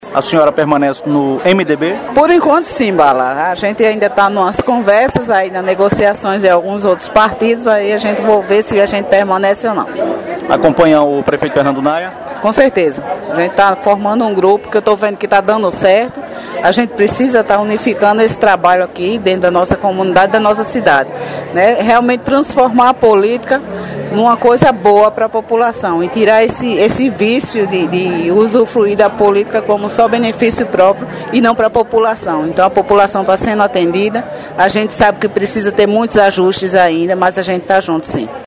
Forte liderança indígena e eleitoral, a vereadora Claudecir Braz (Cacique Cal/MDB), participou na noite deste sábado, 17, da plenária do ODM (Orçamento Democrático Municipal) na Vila Regina, em Rio Tinto.